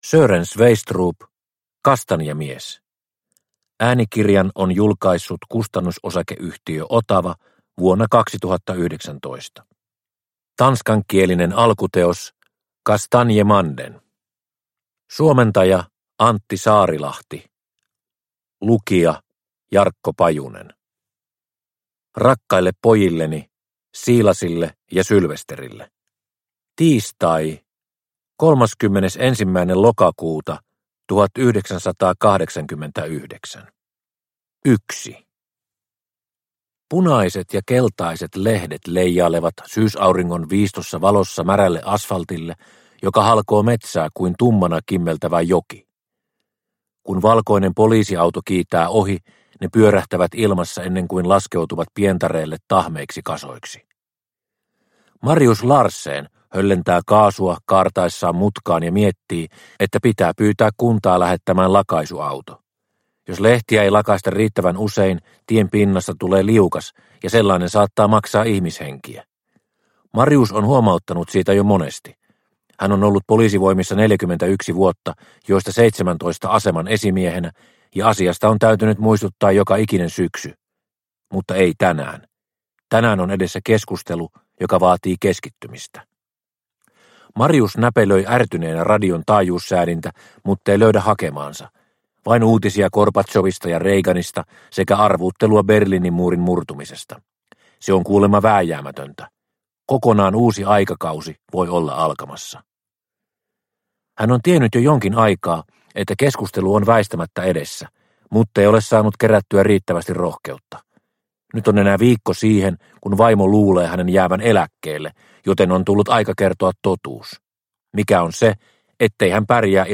Kastanjamies – Ljudbok – Laddas ner